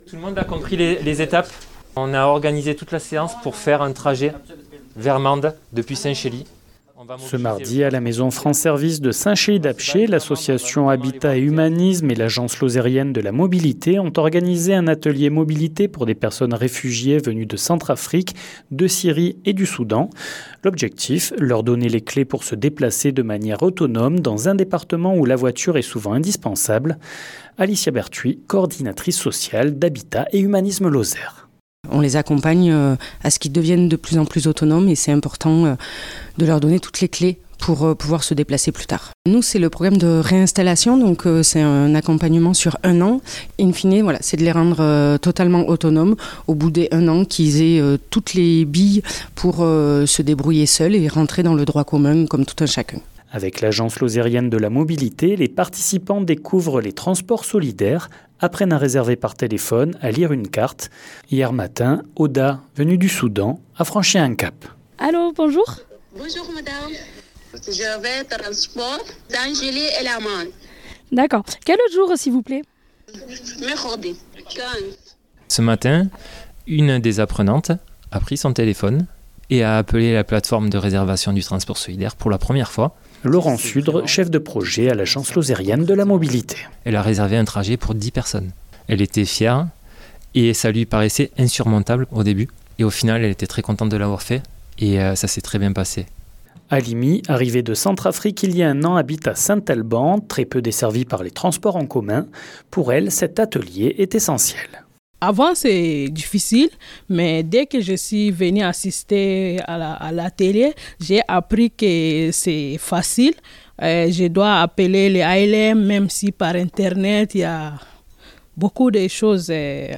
Hier, à Saint-Chély-d’Apcher, L’agence Lozérienne de la mobilité et l’association Habitat et Humanisme Lozère ont proposé un atelier sur les questions de mobilité. Reportage de 48FM.